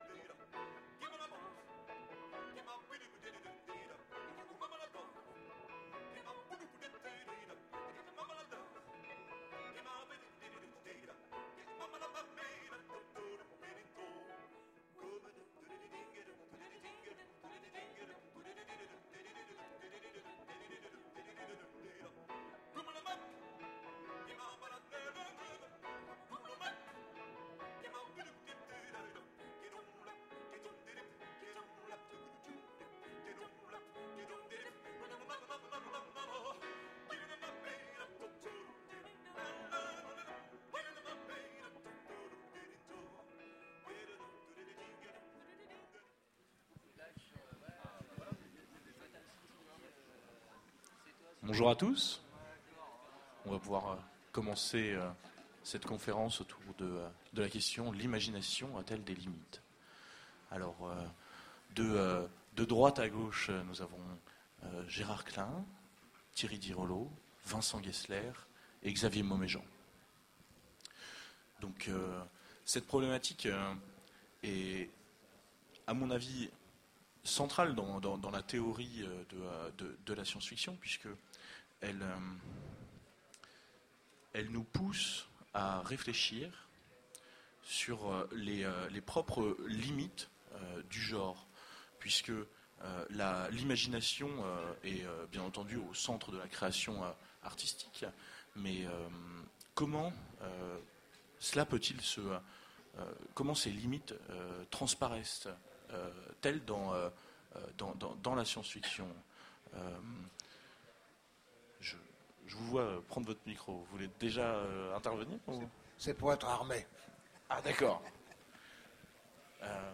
Voici l'enregistrement de la conférence " L'Imagination a-t-elle des limites ? " aux Utopiales 2010. Peut-on dire que l’imagination est limitée par ce que l’on connaît ? L’imagination est-elle capable de tout ou est-elle le fruit de fondations qui la bloqueraient ?